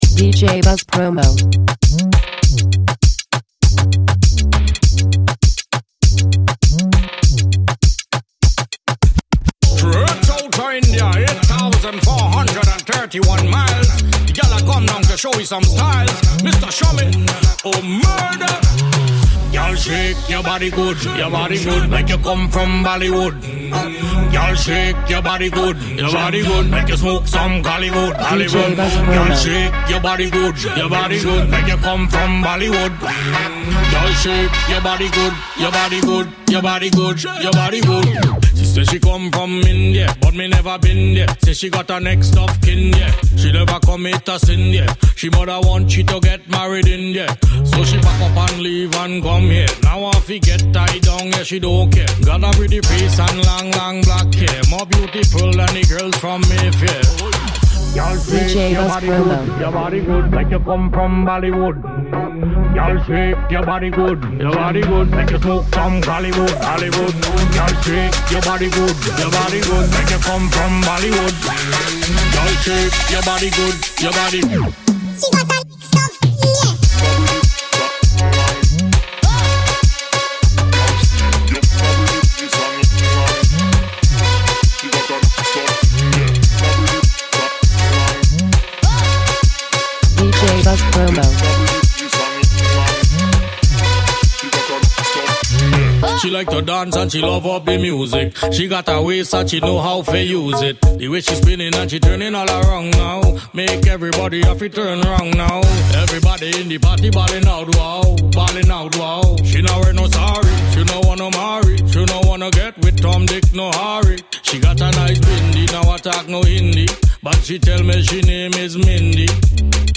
club banger
with a fantastic blend of reggae and Moombahton.